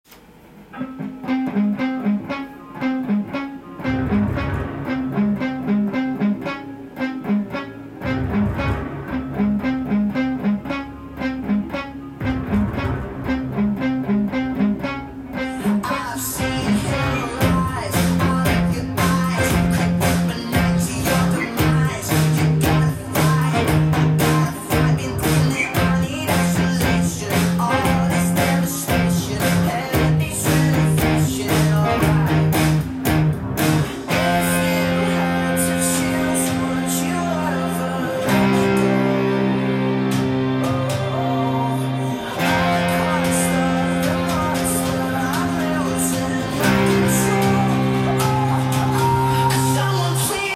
音源にあわせて譜面通り弾いてみました
主にブリッジミュートでエレキギターで弾いていくと
イントロは２小節のオドロドロしいマイナー系フレーズ
入り混じったカッコいいリズムになっています。
ベースとユニゾンできるパワーコードの伴奏になっていますので